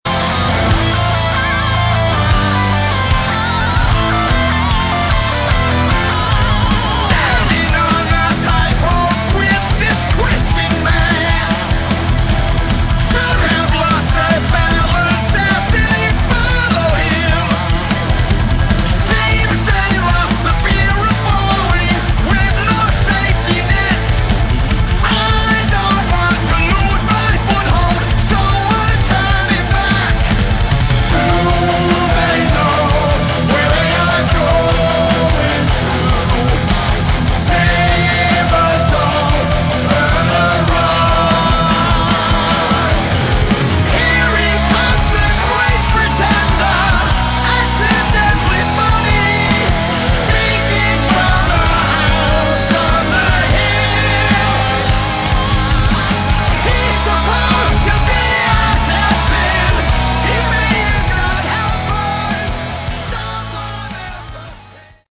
Category: Hard Rock
vocals
guitars